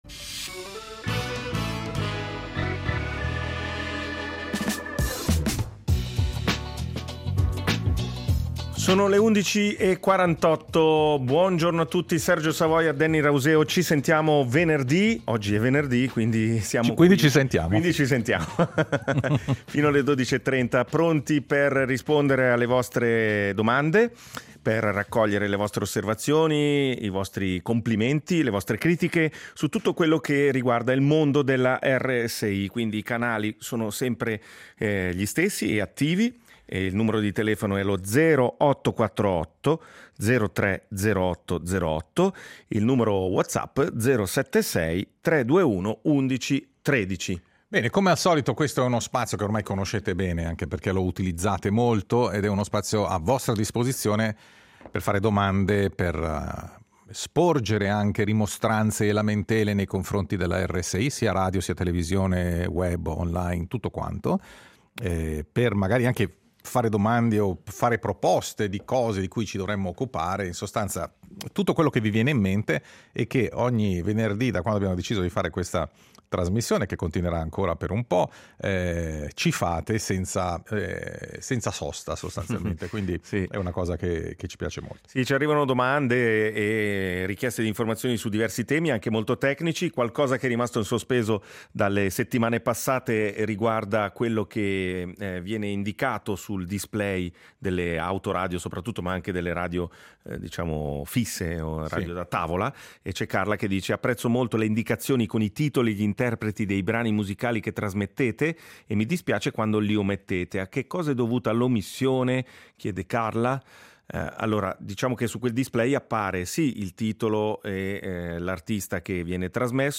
Ogni venerdì, dalle 11.45 alle 12.30, “Ci sentiamo venerdì” è il tavolo radiofonico dove ci si parla e ci si ascolta.